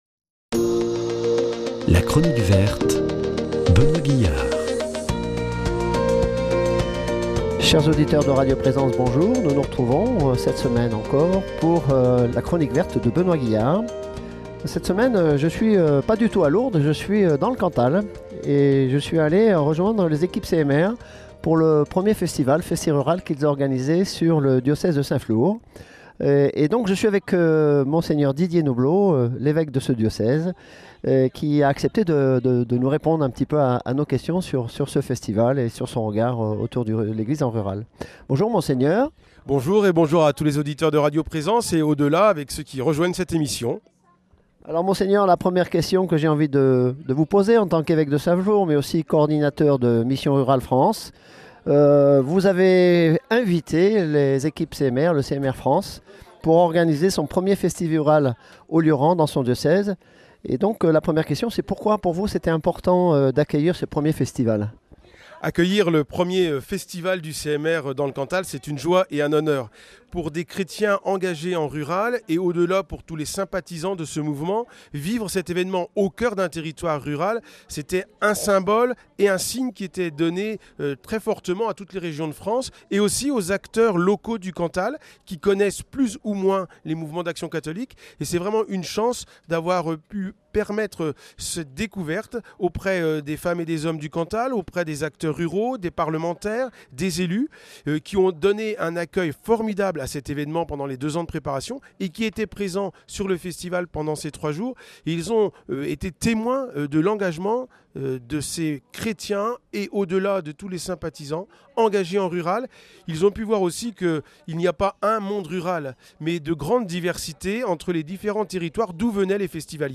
Rencontre avec Mgr Didier NOBLOT, Évêque du diocèse de St Flour, à l’occasion de la tenue du 1er festival organisé par le CMR, "le Festi’Rural", avec plus de 1500 festivaliers venus de toute la France pour fêter le Rural .